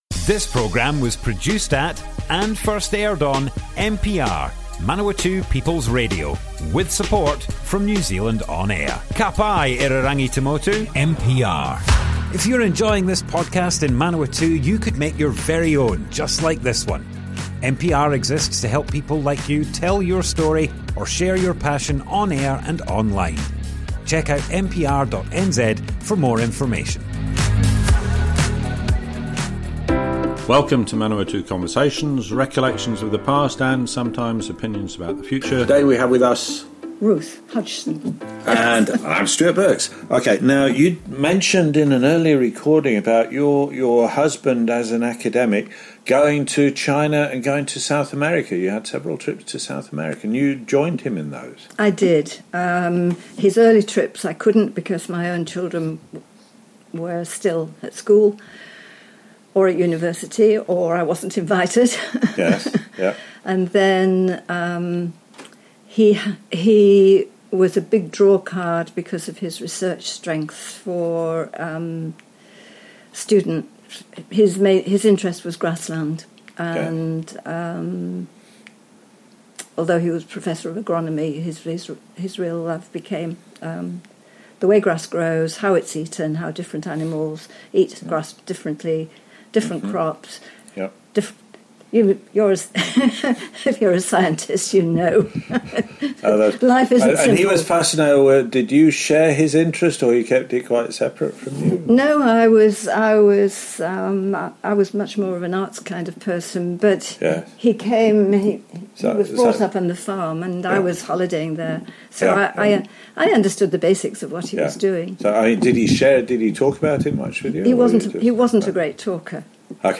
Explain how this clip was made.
Manawatu Conversations More Info → Description Broadcast on Manawatu People's Radio, 29th July 2025.